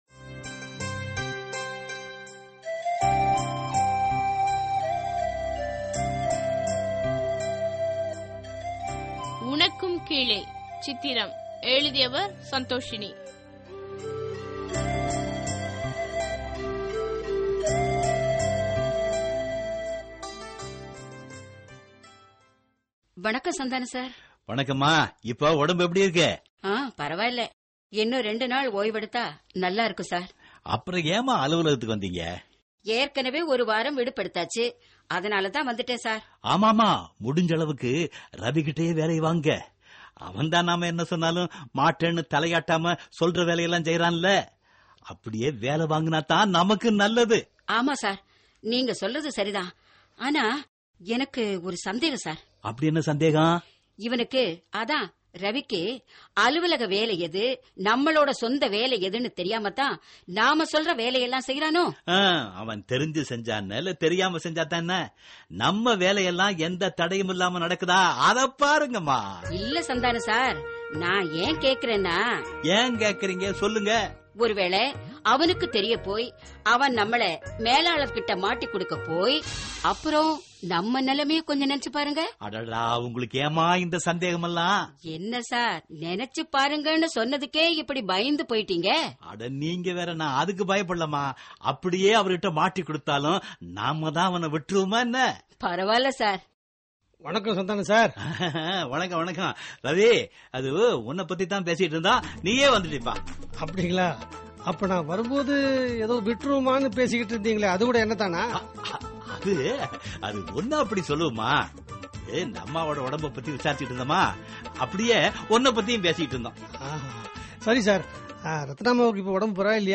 Tamil Dramas Social Drama